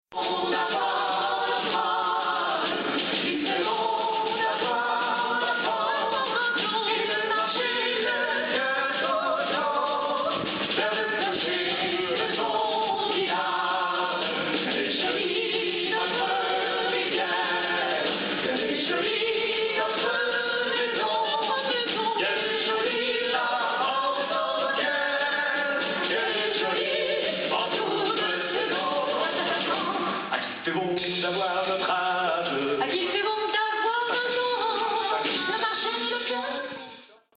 spectacle musical de
medley (en direct) Lacoste 2012